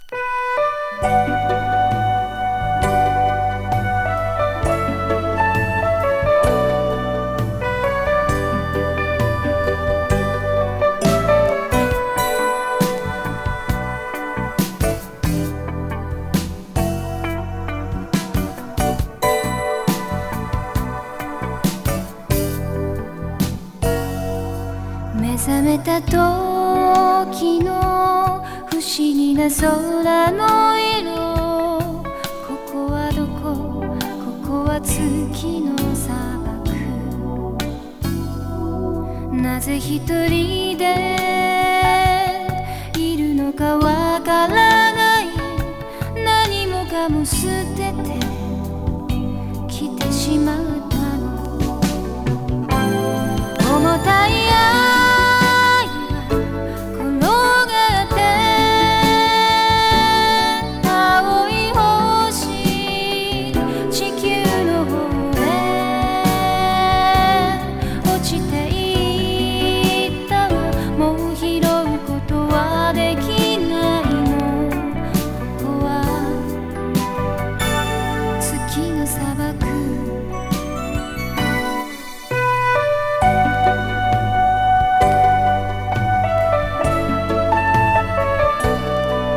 ライクなヨーロピアン歌謡